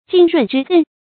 浸润之谮 jìn rùn zhī zèn
浸润之谮发音